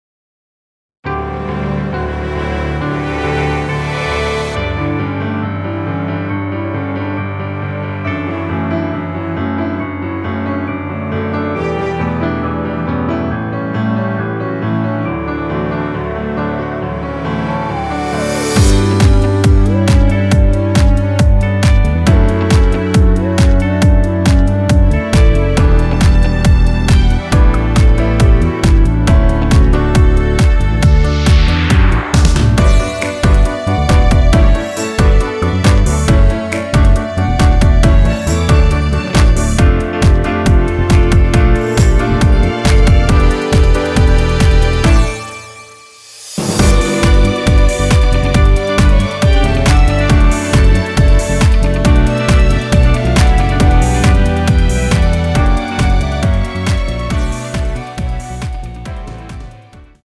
원키에서(-2)내린 멜로디 포함된 MR 입니다.
엔딩이 페이드 아웃이라?노래 하시기 좋게 엔딩을 만들어 놓았습니다.
앞부분30초, 뒷부분30초씩 편집해서 올려 드리고 있습니다.
중간에 음이 끈어지고 다시 나오는 이유는